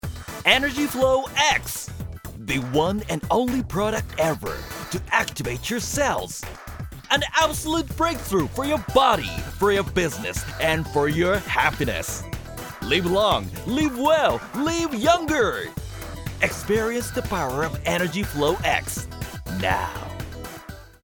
una voz de barítono enérgica y confiable
Muestras de voz en idiomas extranjeros
Demo comercial